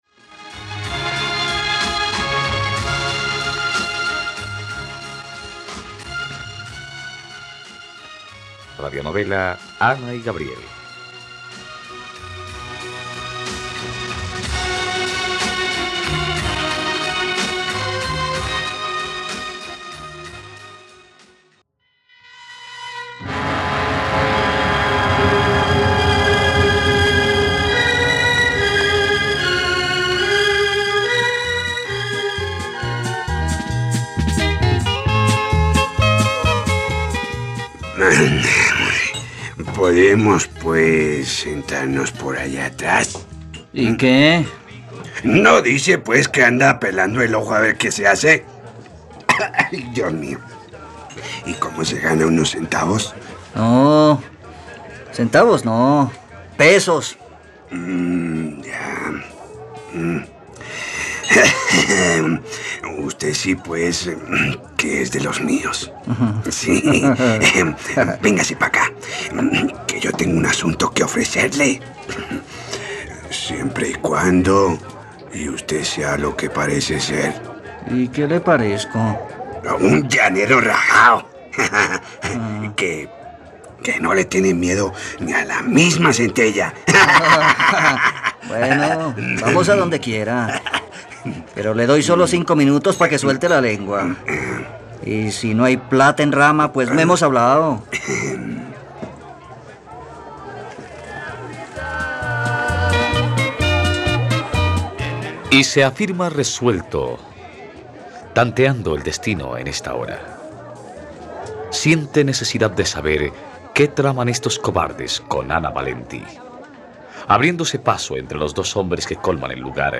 Ana y Gabriel - Radionovela, capítulo 119 | RTVCPlay